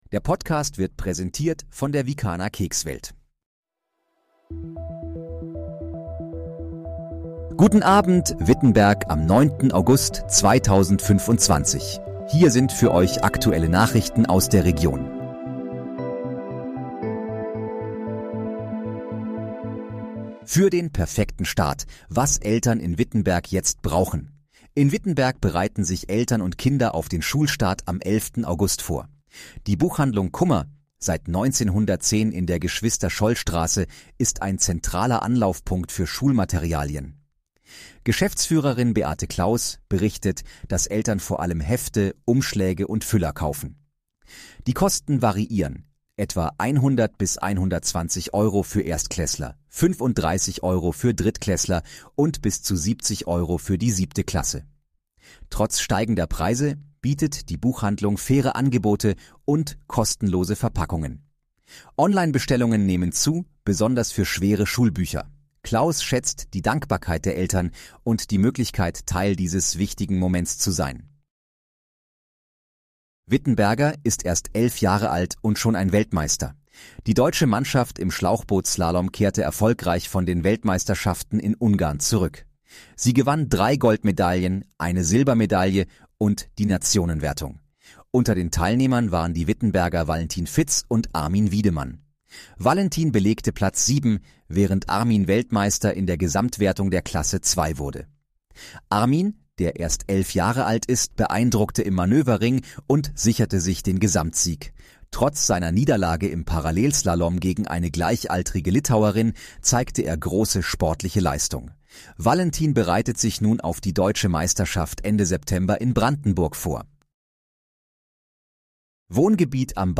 Guten Abend, Wittenberg: Aktuelle Nachrichten vom 09.08.2025, erstellt mit KI-Unterstützung
Nachrichten